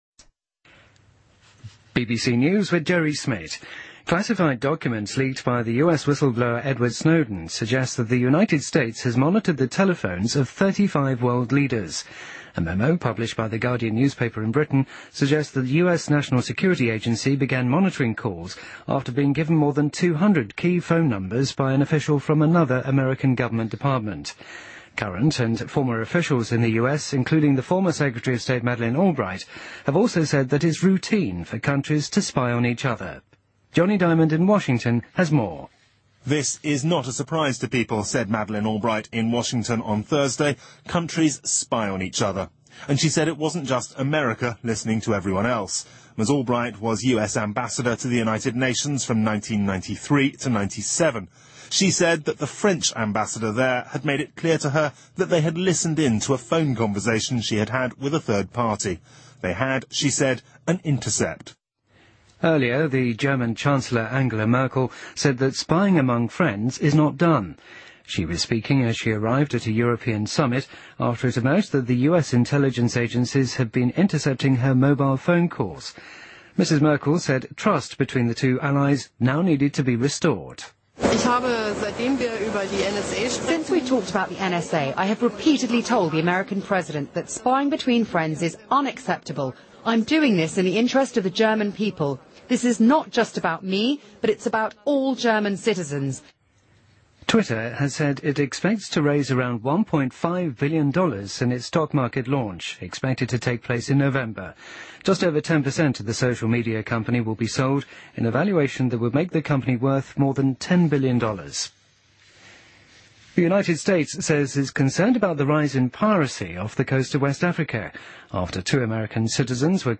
BBC news,斯诺登曝光文件表明美国监控全球35位领导人的电话